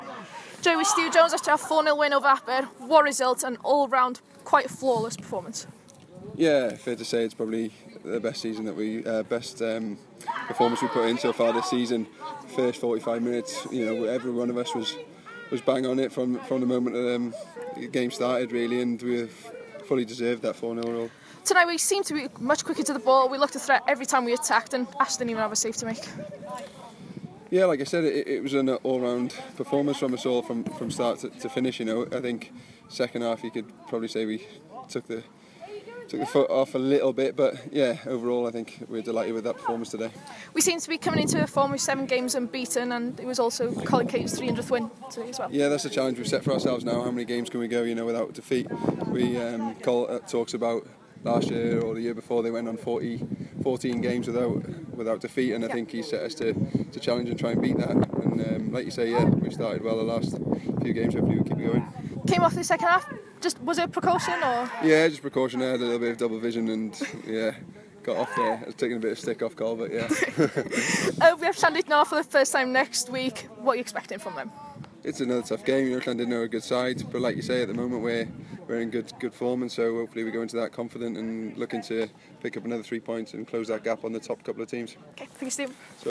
post-match v Aberystwyth Town